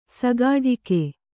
♪ sagāḍhike